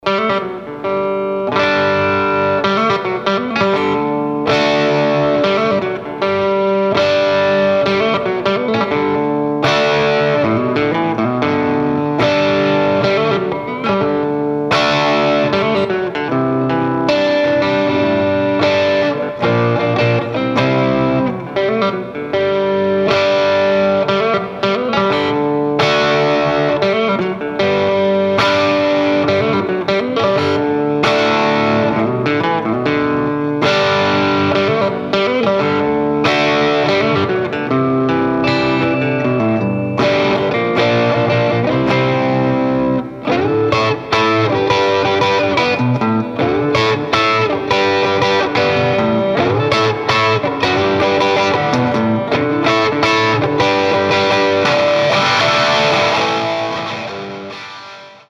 TONE SAMPLES - All clips plugged straight into amp unless noted.
Less than one thousandth of a watt, barely audible without mic.